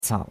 cao3.mp3